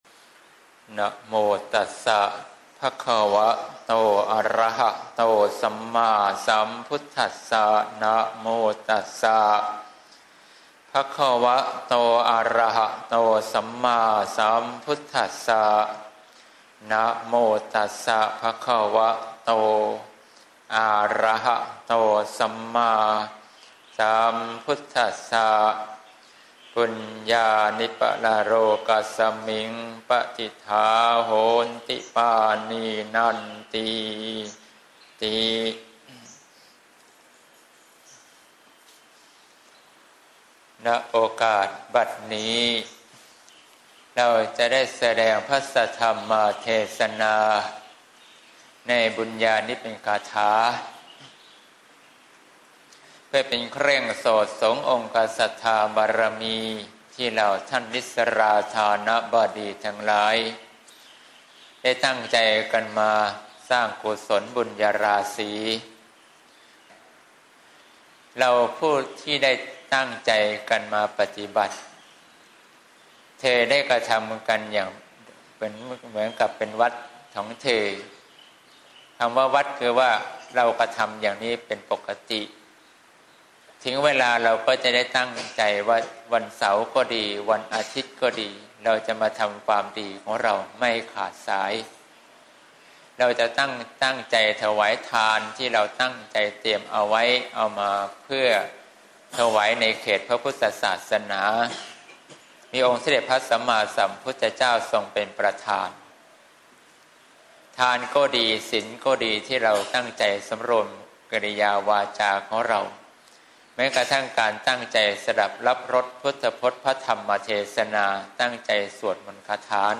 เทศน์จิตที่พร้อมฝึก (เสียงธรรม ๑๑ ส.ค. ๖๘)